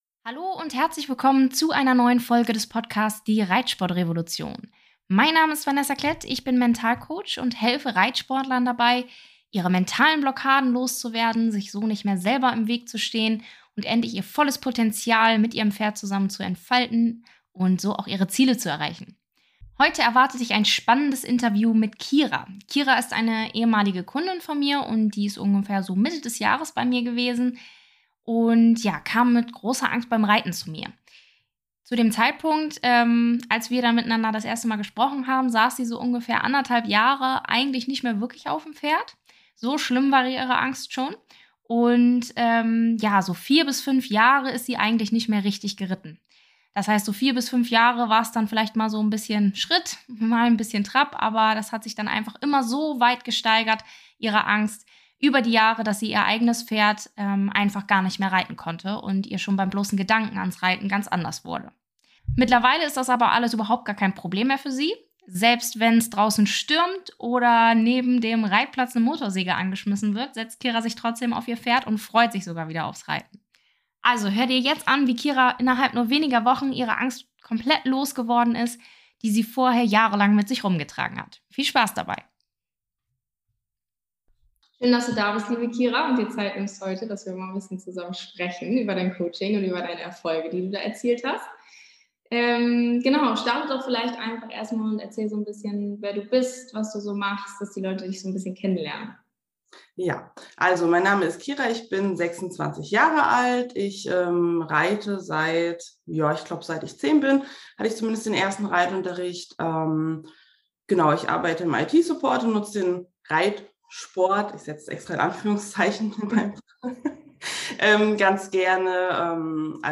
#010: Vom Angstreiter zum Allwetterreiter (Interview